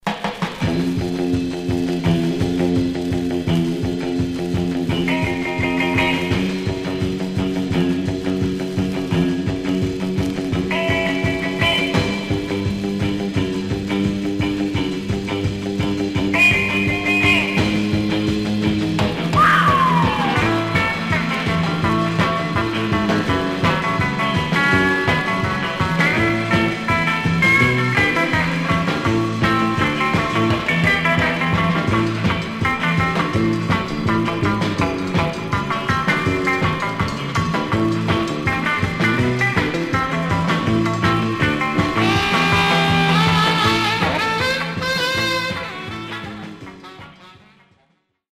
Surface noise/wear
Mono
R & R Instrumental Condition